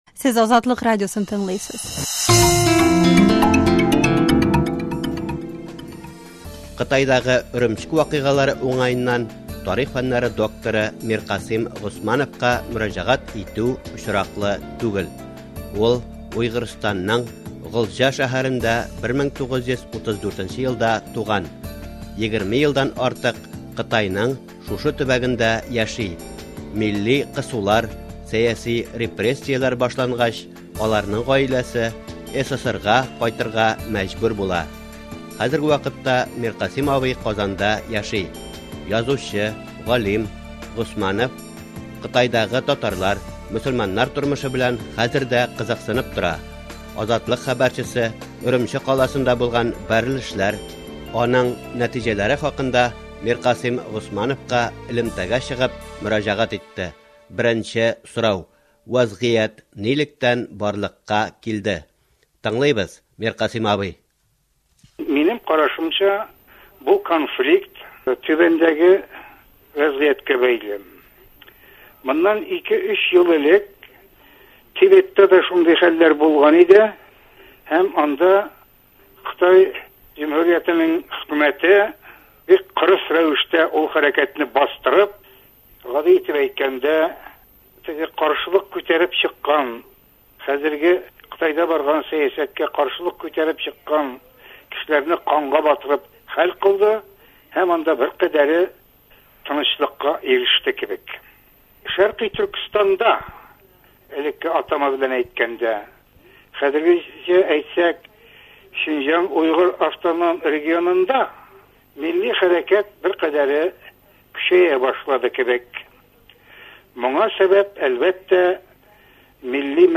Könçığış Törkestandagı wazğiät turında Mirqasım Gosmanov belän äñgämä